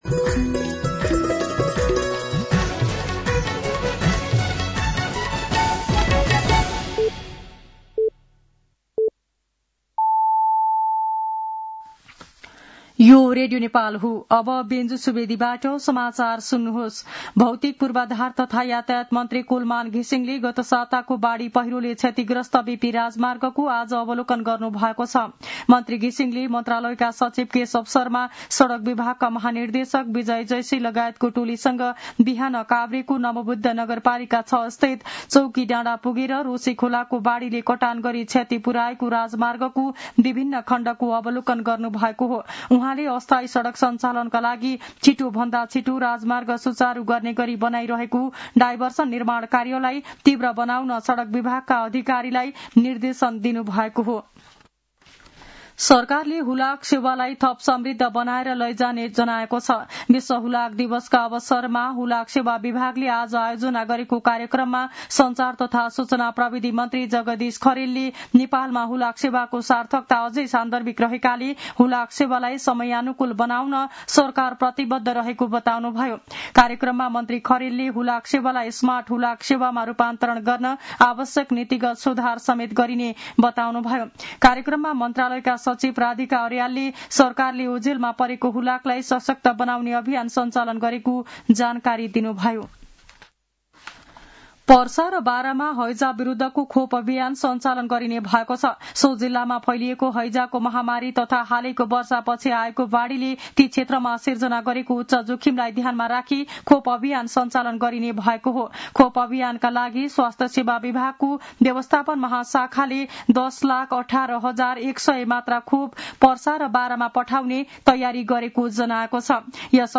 साँझ ५ बजेको नेपाली समाचार : २३ असोज , २०८२
5.-pm-nepali-news-1-2.mp3